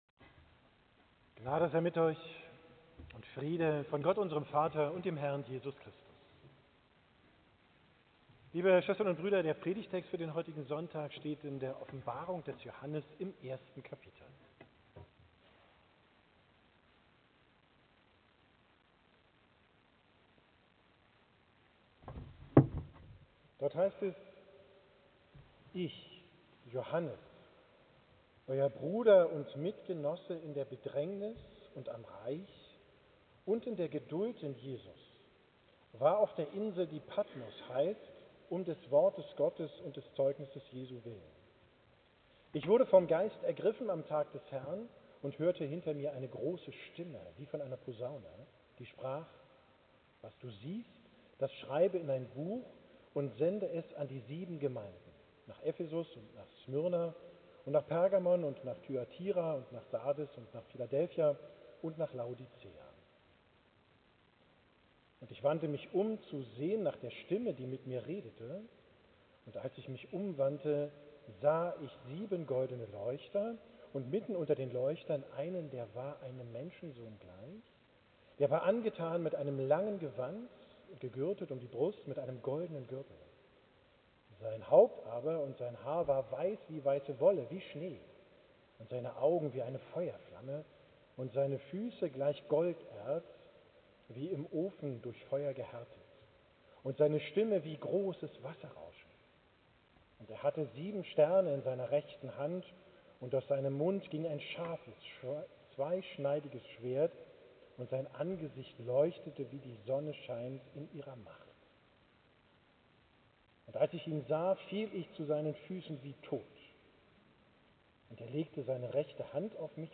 Predigt vom letzten Sonntag nach Epiphanias, 1.